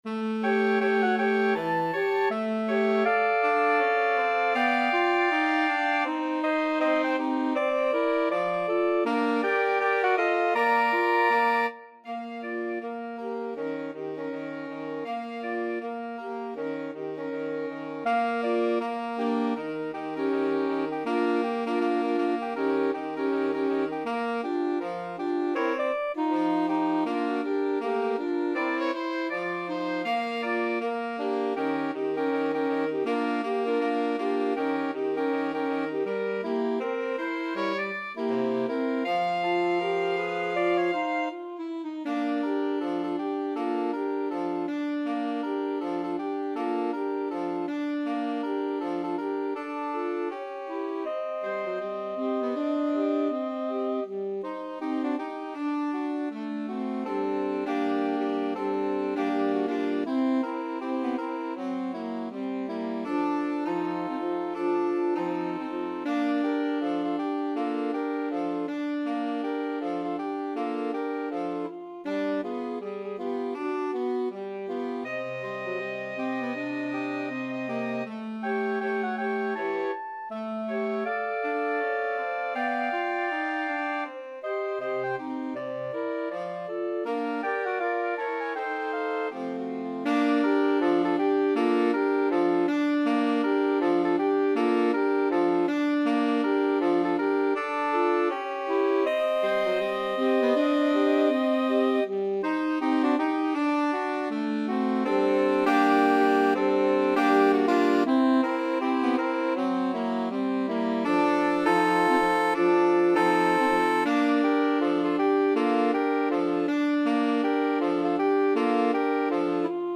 Jazz Saxophone Quartet